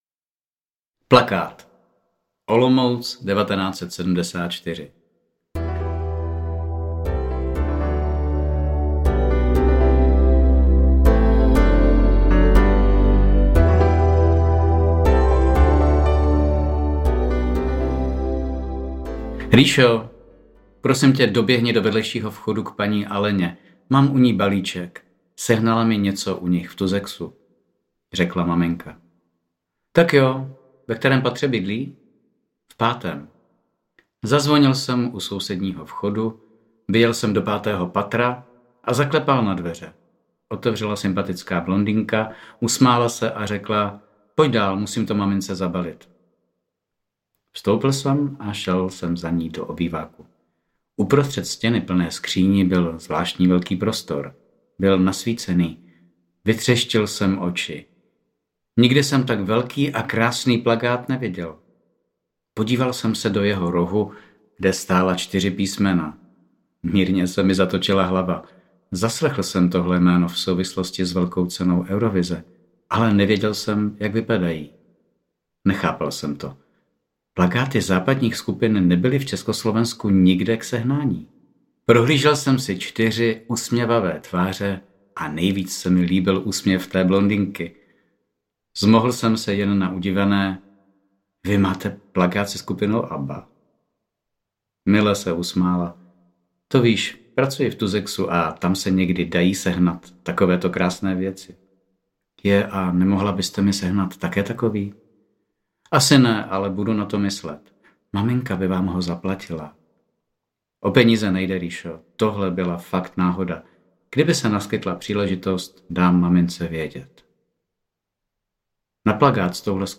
ABBA za železnou oponou audiokniha
Ukázka z knihy